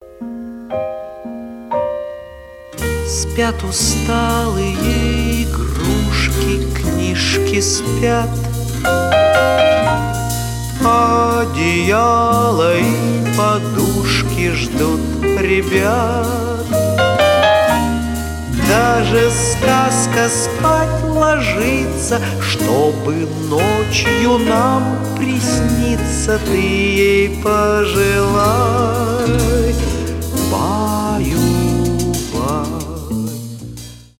колыбельные